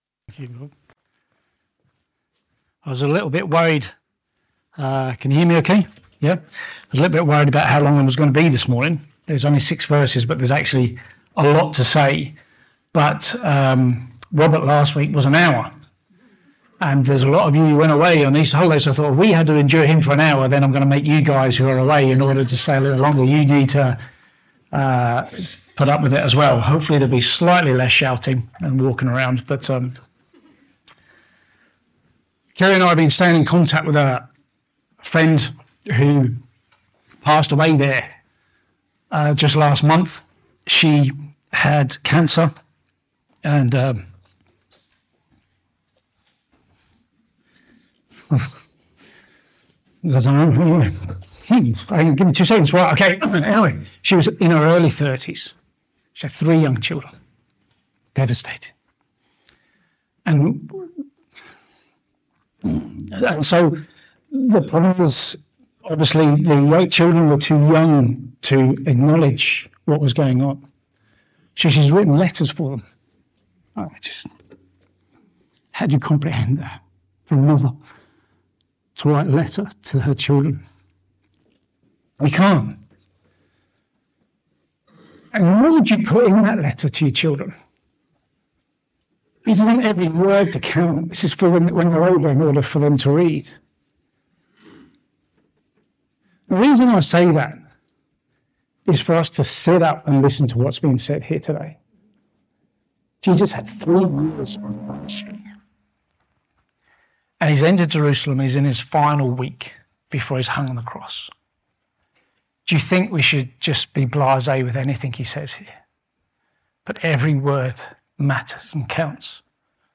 Sermons - The Bridge Church Strabane